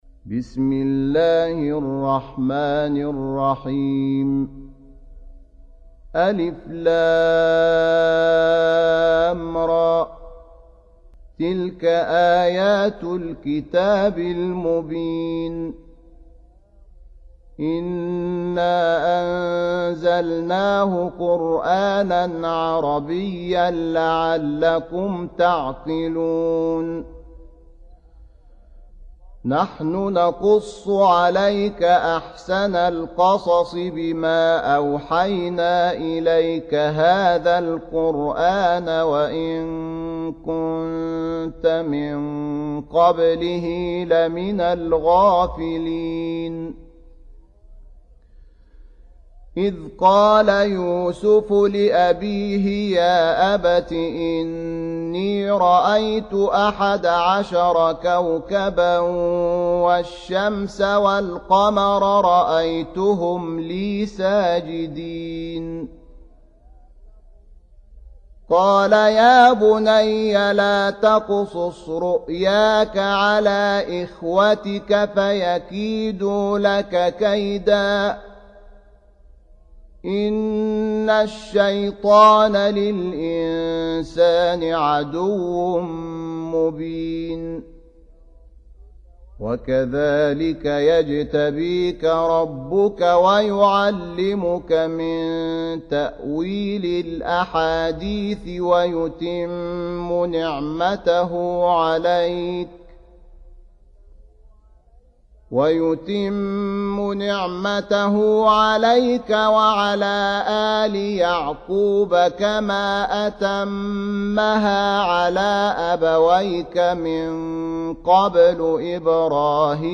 12. Surah Y�suf سورة يوسف Audio Quran Tarteel Recitation
Surah Repeating تكرار السورة Download Surah حمّل السورة Reciting Murattalah Audio for 12. Surah Y�suf سورة يوسف N.B *Surah Includes Al-Basmalah Reciters Sequents تتابع التلاوات Reciters Repeats تكرار التلاوات